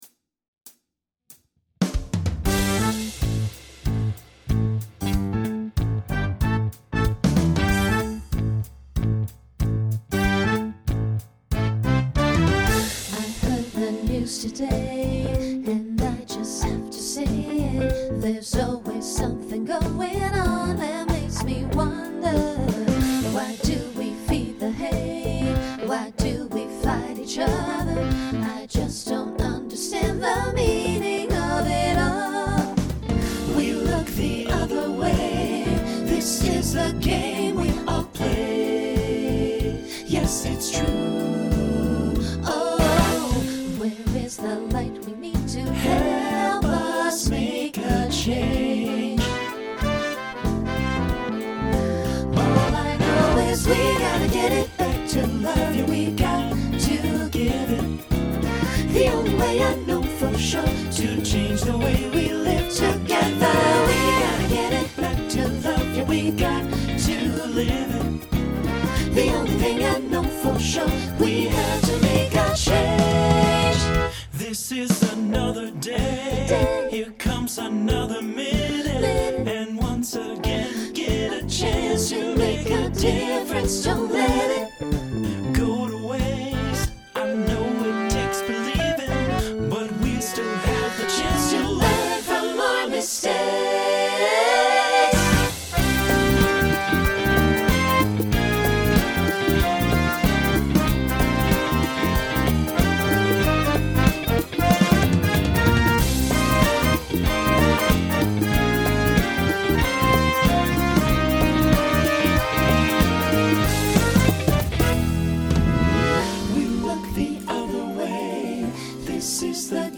Rock , Swing/Jazz Instrumental combo
Voicing SATB